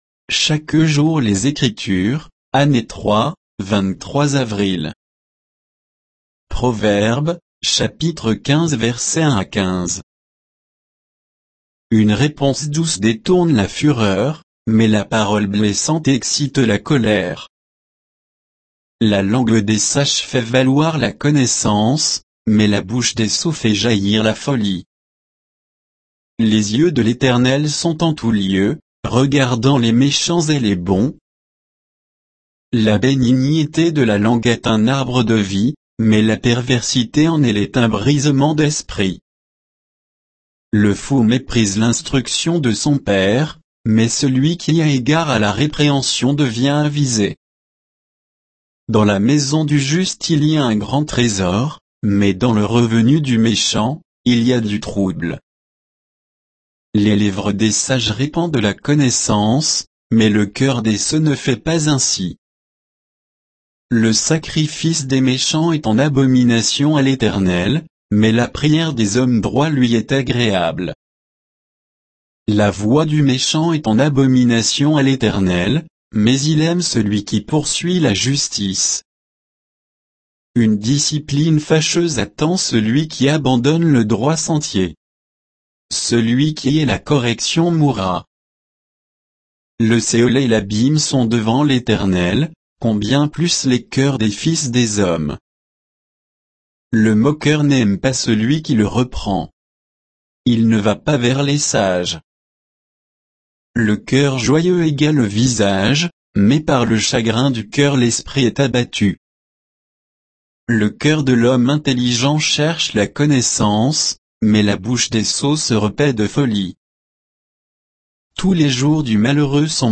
Méditation quoditienne de Chaque jour les Écritures sur Proverbes 15, 1 à 15